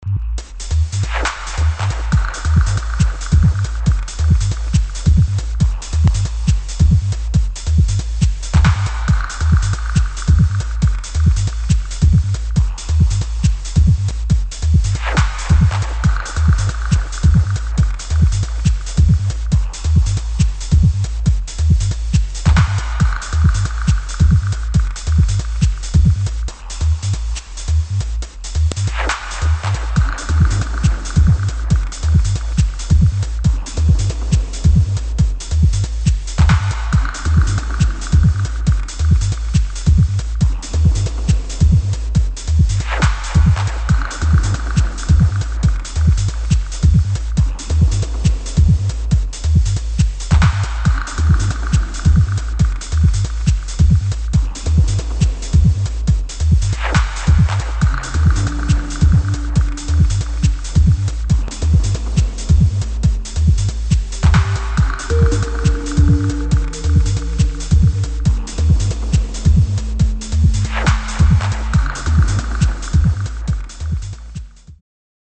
[ BASS / ELECTRONIC / TECHNO ]